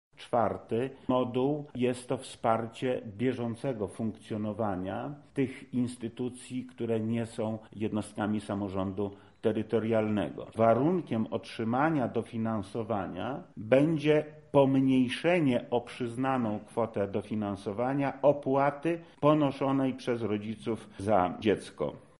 To moduł uzupełniający – tłumaczy wojewoda: